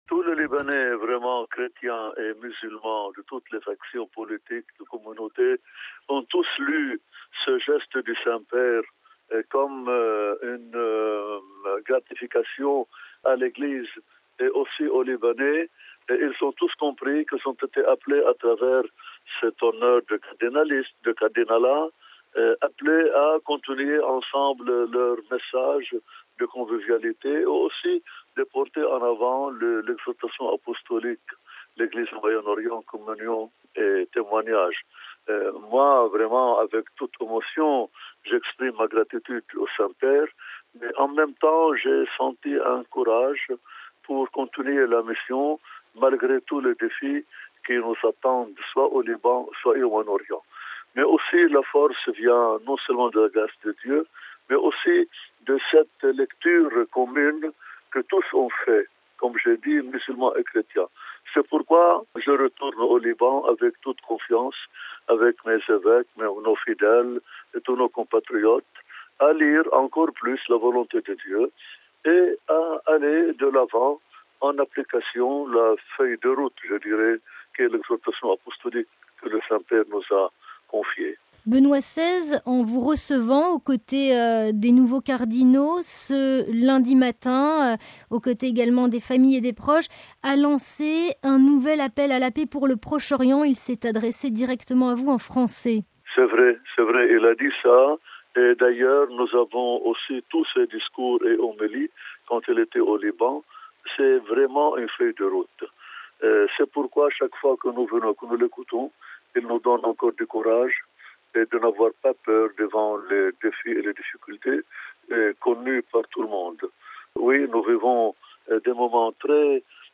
Nous avons rencontré le cardinal Raï, qui se fait le porte-parole des chrétiens de la région et se dit inquiet face à la situation en Syrie, en Terre Sainte, en Irak, en Egypte et au Liban. Il évoque « la montée de l’intégrisme et du fondamentalisme ».